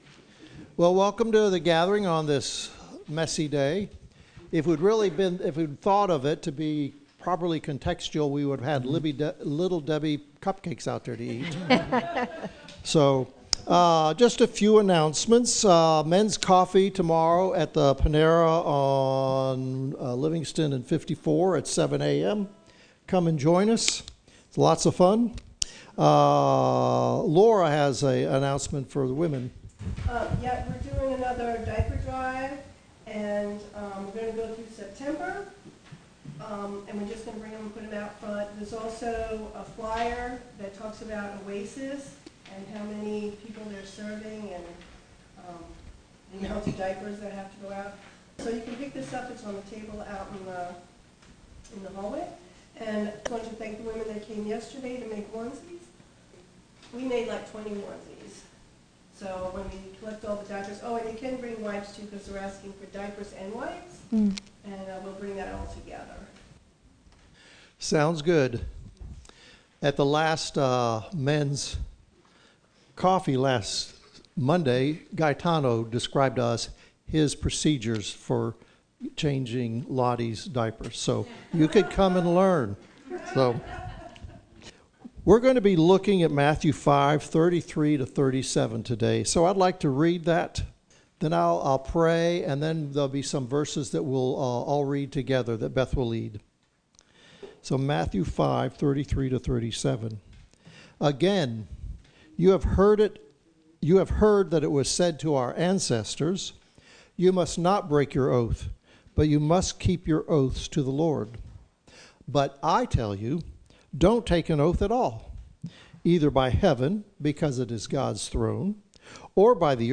Matthew 5:31-32 Service Type: Gathering Some Bibles label this section of the Sermon on the Mount “Oaths.”